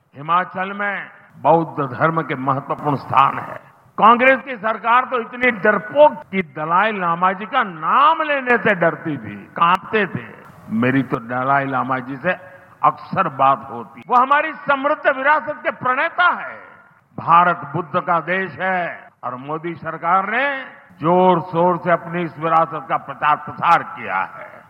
རྒྱ་གར་སྲིད་བློན་མོ་དྷི་མཆོག་གིས་གནང་བའི་གསུང་བཤད།